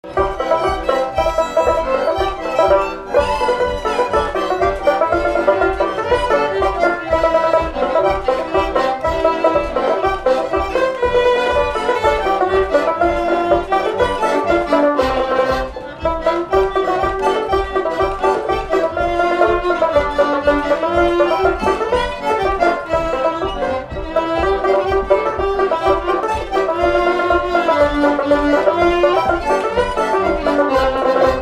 Instrumental
danse : séga
Pièce musicale inédite